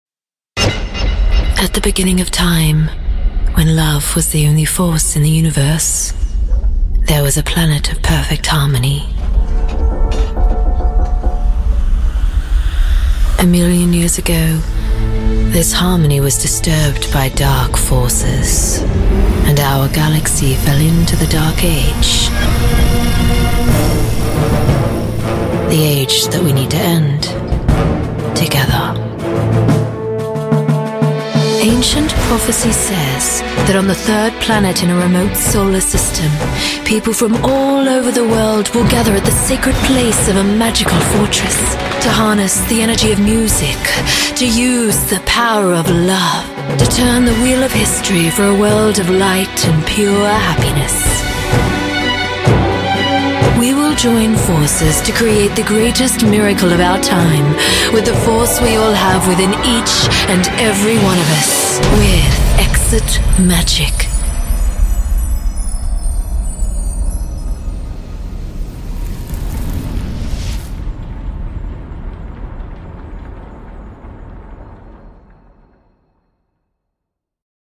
Videogames
Neumann U87
Soprano